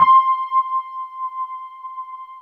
WETRD  C5 -R.wav